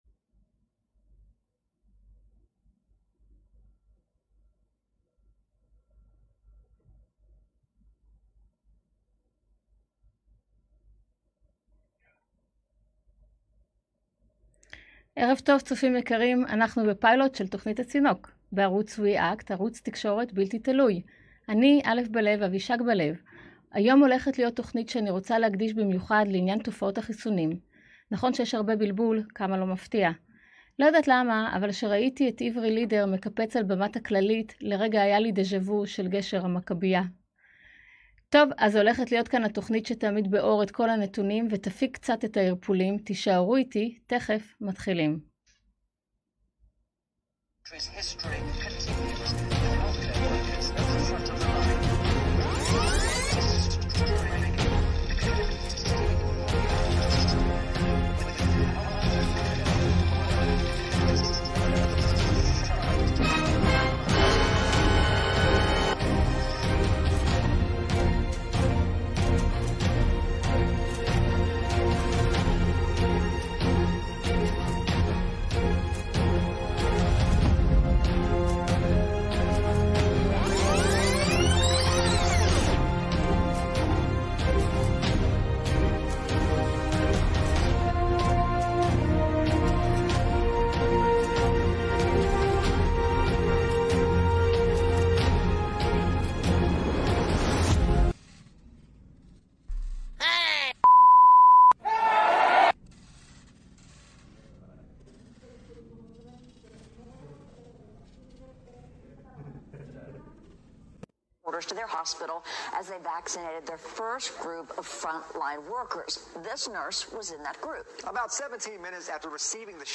שגעת החיסונים - אורחת טלפונית